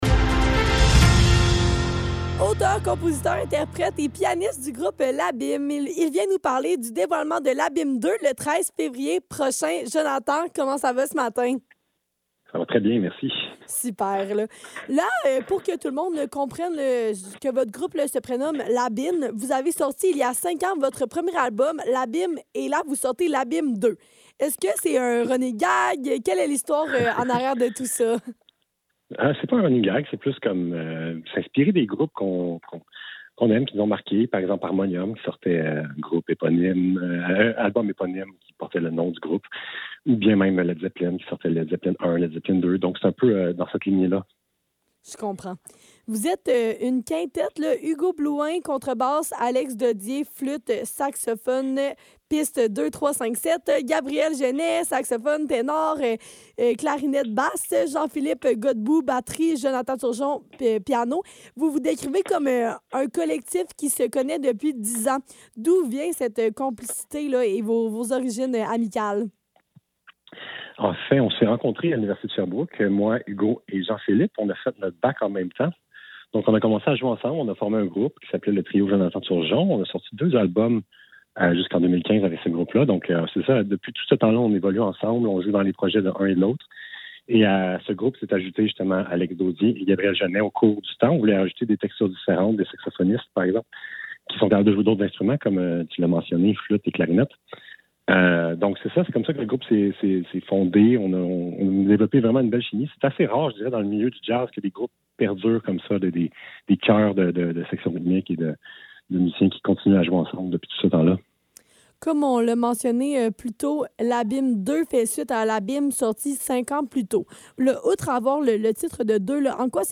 Le Neuf - Entrevue avec le quintette L'abîme dans le cadre de leur spectacle de lancement à l'Université Bishop - 9 février 2026